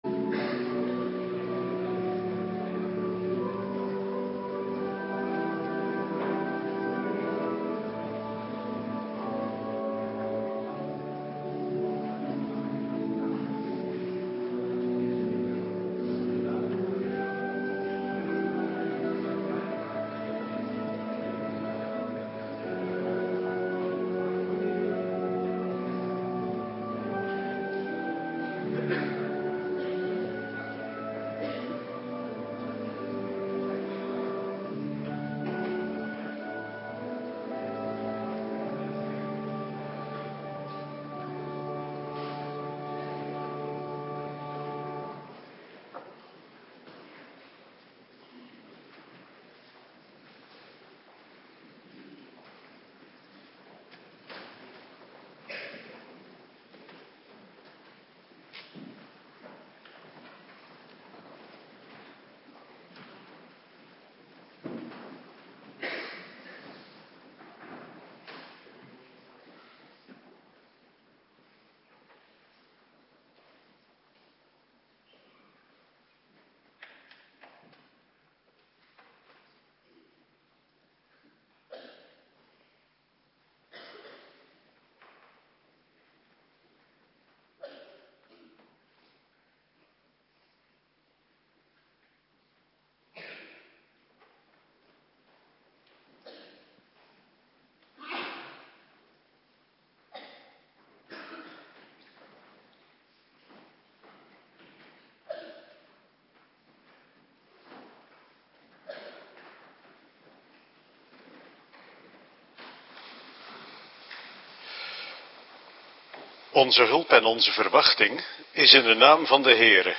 Avonddienst
Locatie: Hervormde Gemeente Waarder